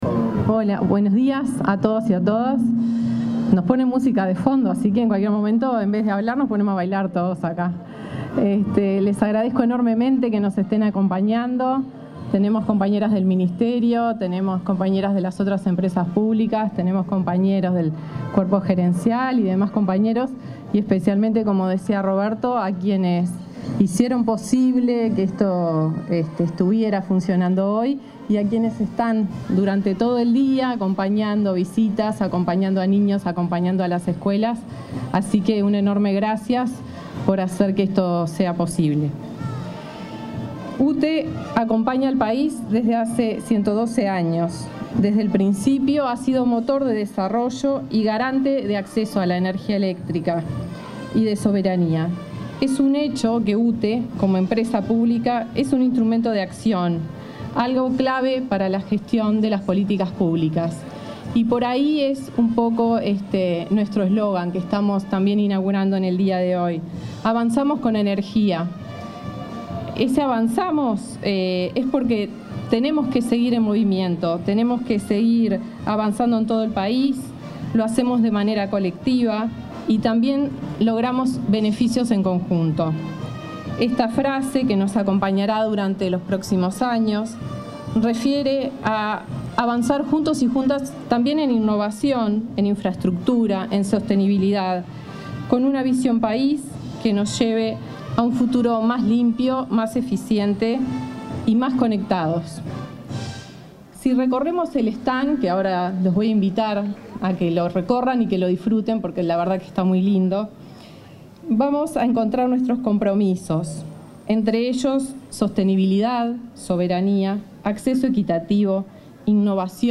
La presidenta de UTE, Andrea Cabrera, disertó en la inauguración del stand de ese organismo en la Expo Prado 2025.